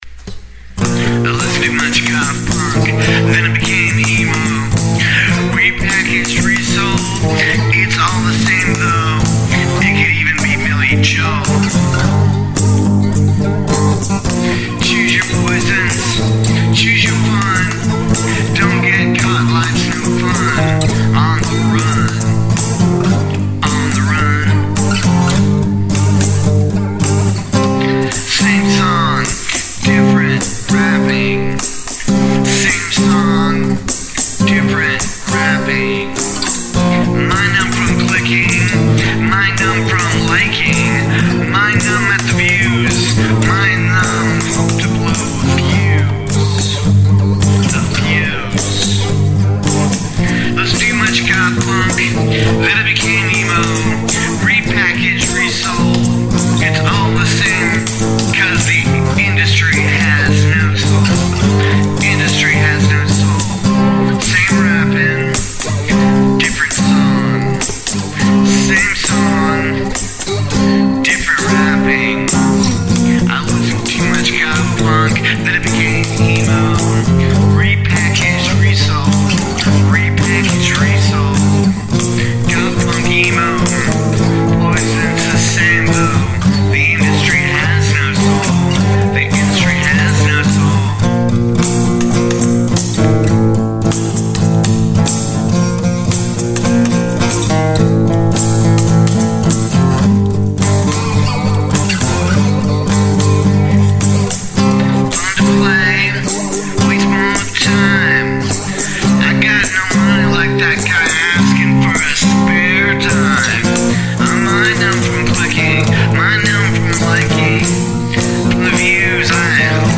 Original Music